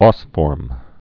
(ôsfôrm)